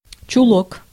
Ääntäminen
Ääntäminen Tuntematon aksentti: IPA: /t͡ɕʉˈlok/ Haettu sana löytyi näillä lähdekielillä: venäjä Käännöksiä ei löytynyt valitulle kohdekielelle.